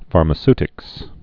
(färmə-stĭks)